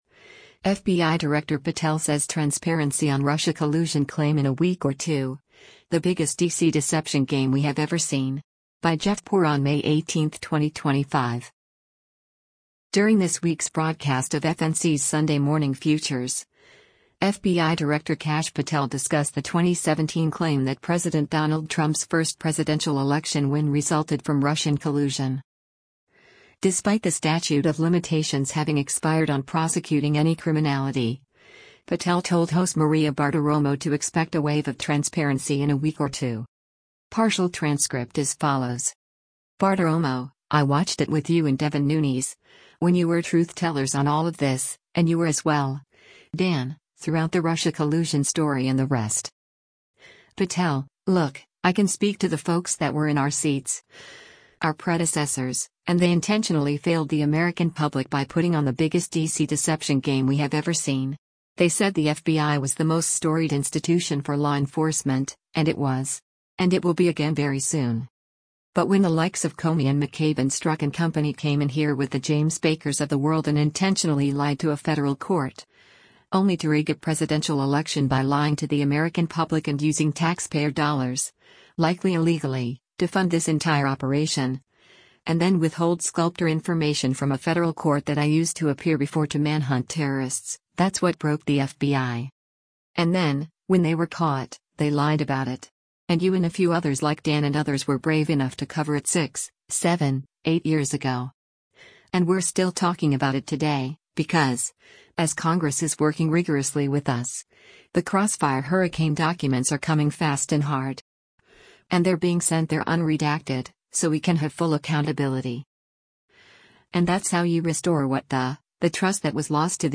During this week’s broadcast of FNC’s “Sunday Morning Futures,” FBI Director Kash Patel discussed the 2017 claim that President Donald Trump’s first presidential election win resulted from Russian collusion.
Despite the statute of limitations having expired on prosecuting any criminality, Patel told host Maria Bartiromo to expect a “wave of transparency” in a week or two.